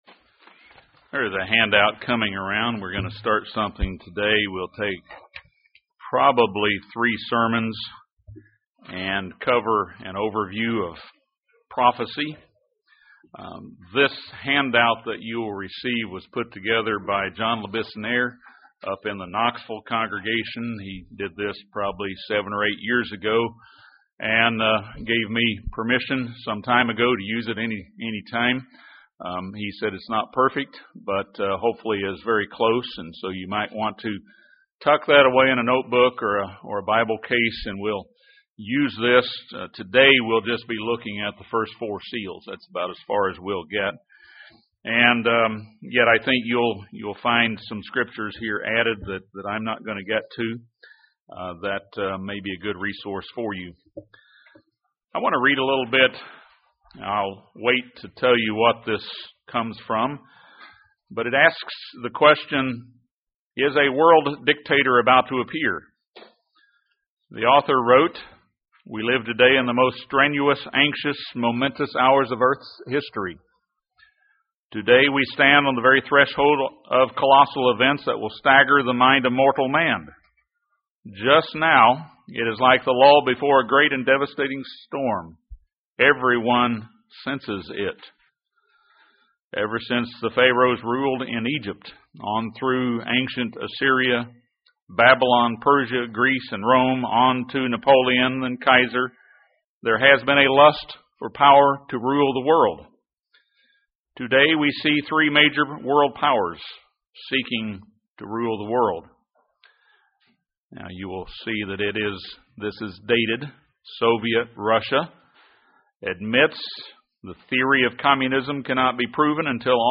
This sermon is part 1 of a three-part series on prophecy. It discusses the first four seals of Revelation: false religion, wars, famine and pestilence.